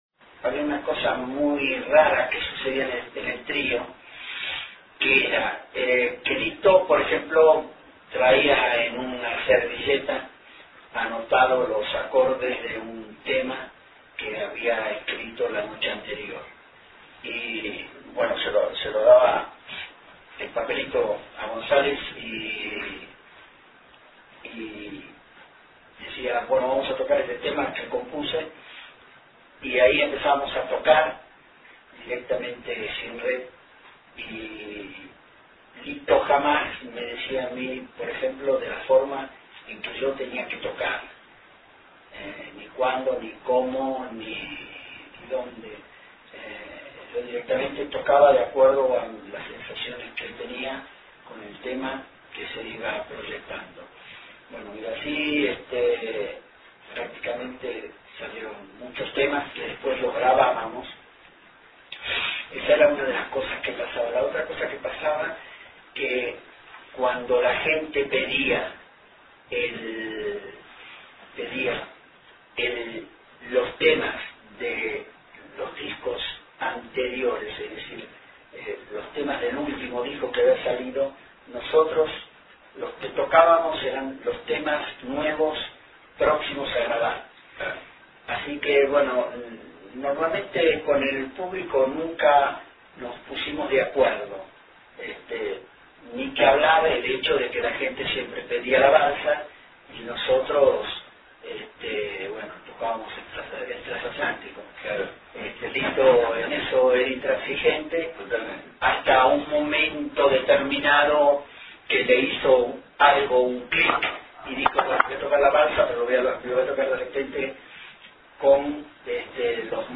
Entrevista
Cuando nos ubicamos en el living de su departamento todo, absolutamente, estuvo envuelto en aires de música.